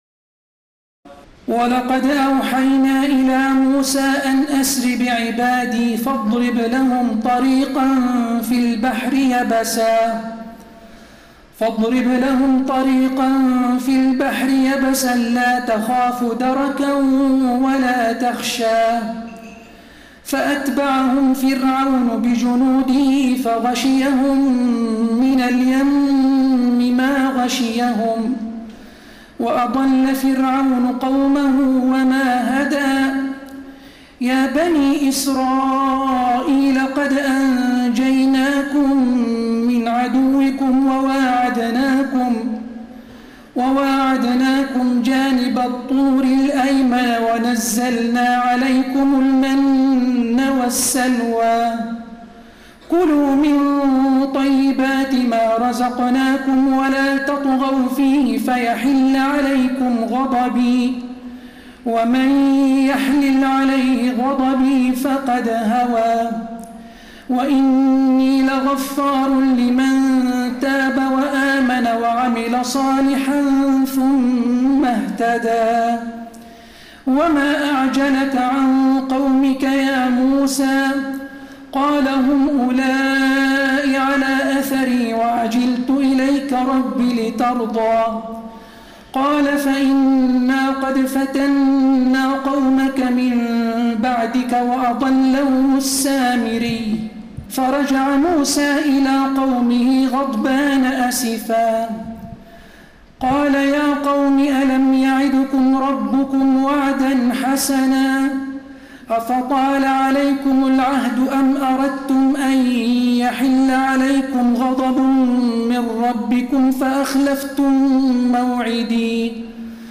تراويح الليلة السادسة عشر رمضان 1432هـ من سورتي طه (77-135) و الأنبياء (1-50) Taraweeh 16 st night Ramadan 1432H from Surah Taa-Haa and Al-Anbiyaa > تراويح الحرم النبوي عام 1432 🕌 > التراويح - تلاوات الحرمين